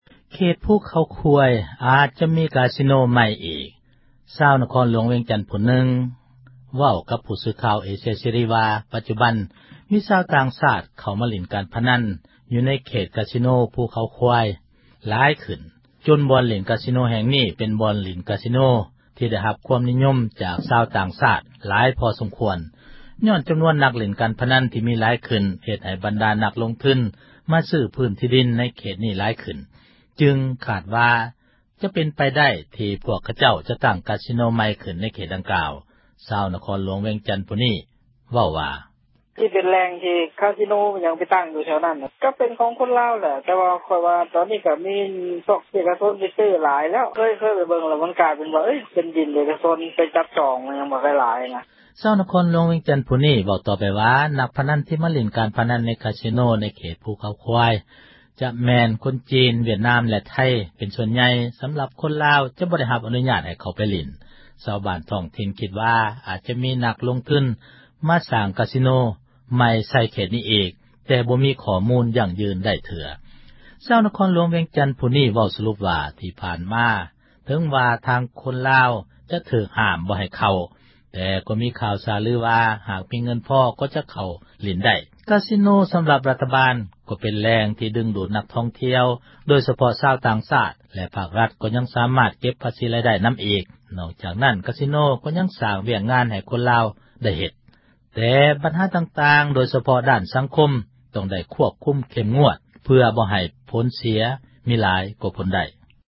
ຊາວນະຄອນຫລວງ ວຽງຈັນ ຜູ້ນຶ່ງເວົ້າກັບ ຜູ້ສື່ຂ່າວ ເອເຊັຍເສຣີ ວ່າປັດຈຸບັນ ມີຊາວຕ່າງຊາດ ມາຫລິ້ນ ການພະນັນ ຢູ່ໃນ ກາຊີໂນ ພູເຂົາຄວາຍ ຫລາຍຂື້ນ.